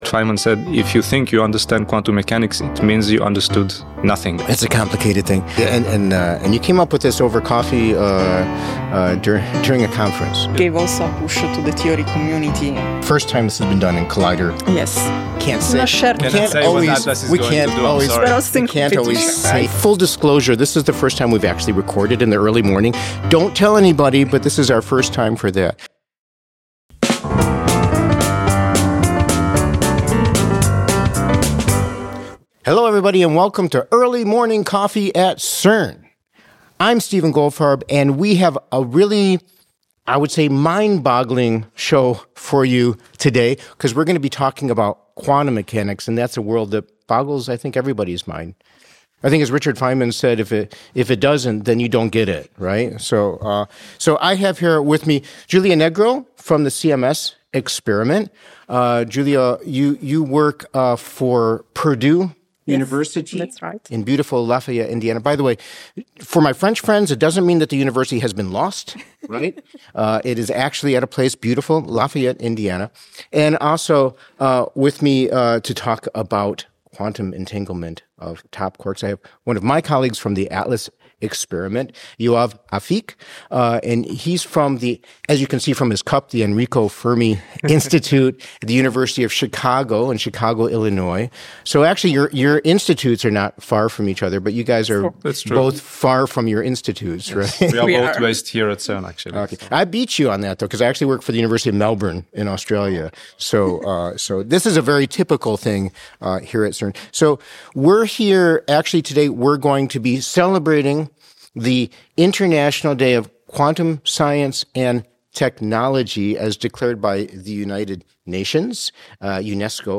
two physicists behind groundbreaking results from the Large Hadron Collider's biggest experiments.